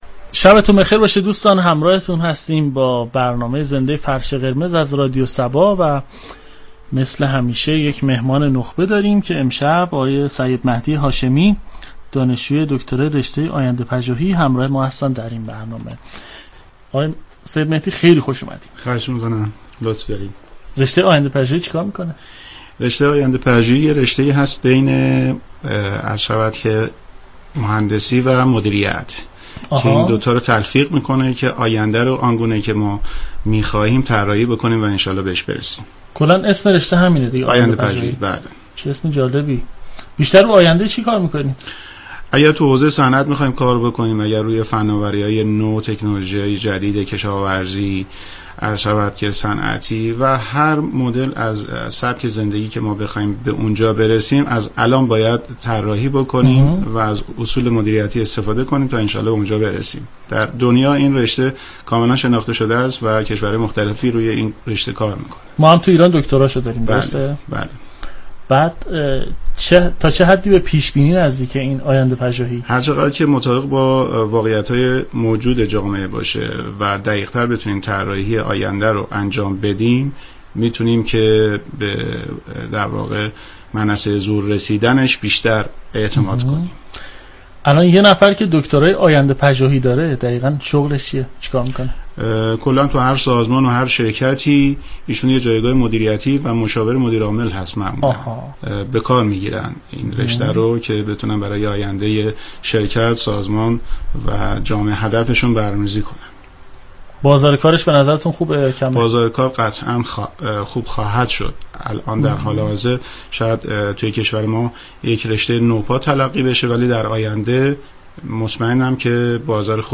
رادیو صبا در برنامه گفتگو محور فرش قرمز به معرفی نخبگان جوان كشور می‌پردازد.